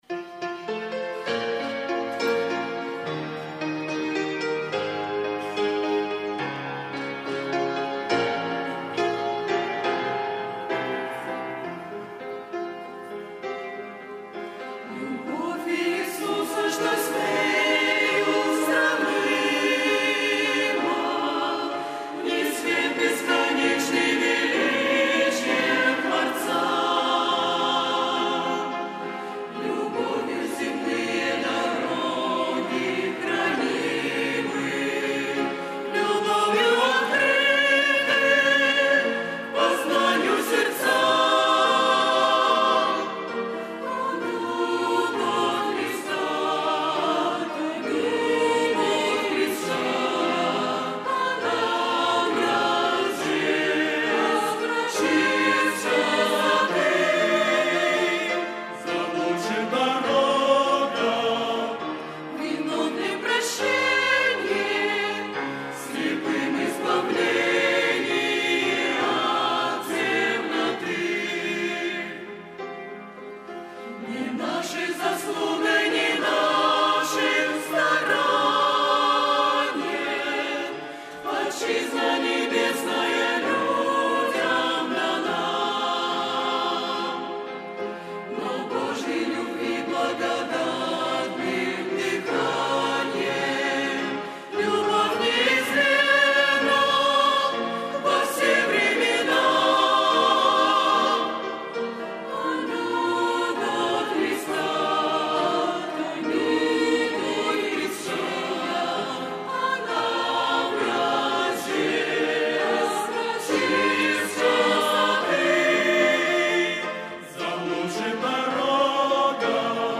Богослужение 29.01.2023
Любовь Иисуса, что с нею сравнимо - Хор (Пение)[